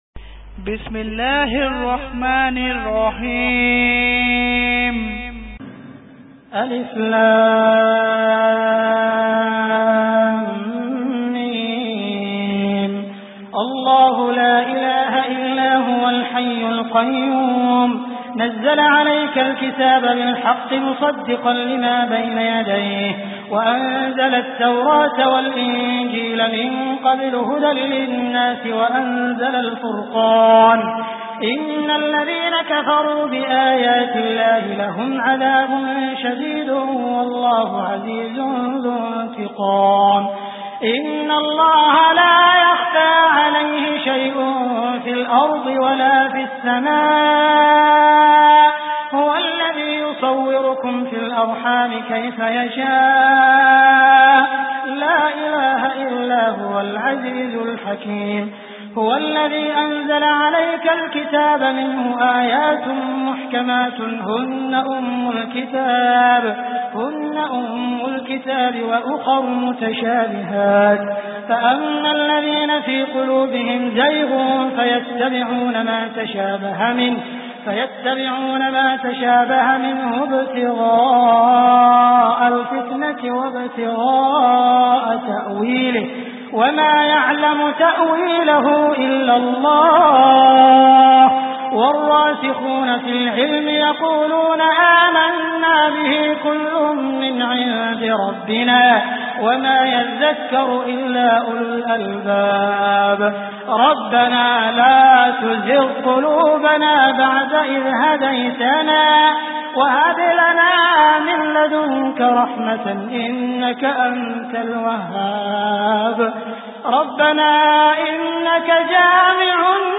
Surah Al Imran MP3 Download By Sheikh Abdul Rahman Al Sudais. Surah Al Imran Beautiful Recitation MP3 Download By Abdul Rahman Al Sudais in best audio quality.